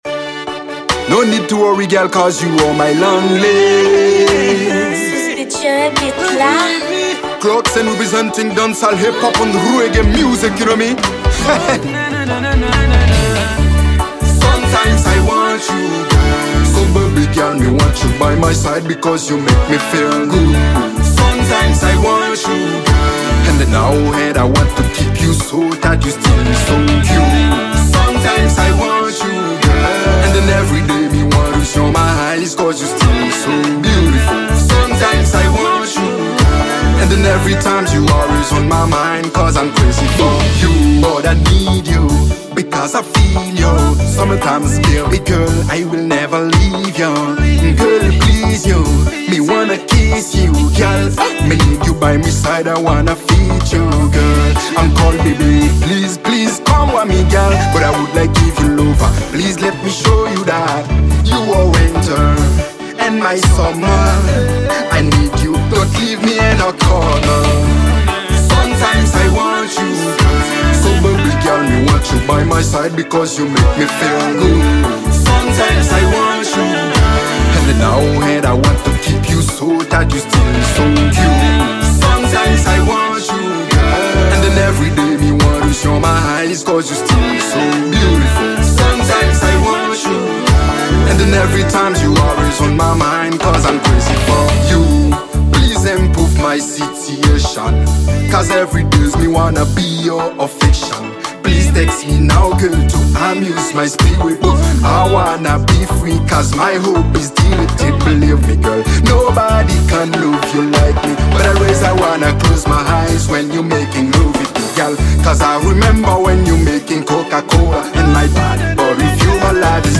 Genre: Reggea.